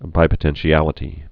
(bīpə-tĕnshē-ălĭ-tē)